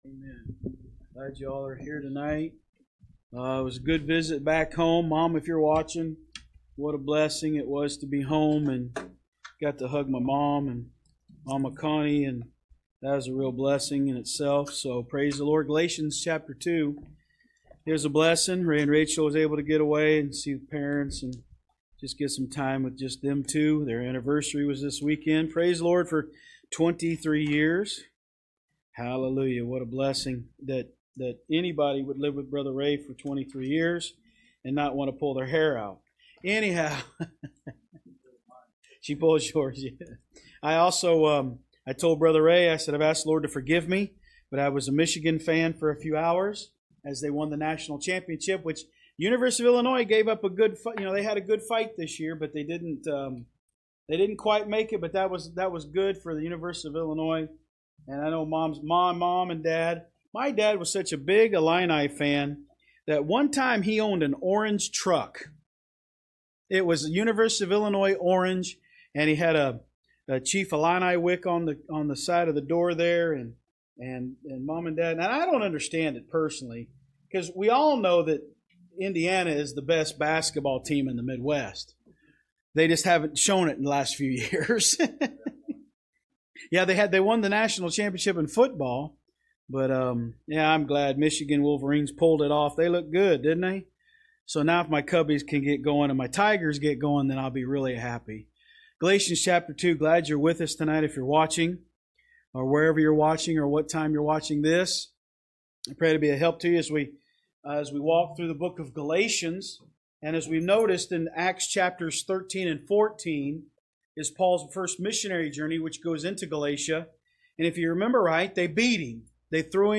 Online Sermons – Walker Baptist Church
From Series: "Wednesday Service"